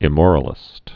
(ĭ-môrə-lĭst)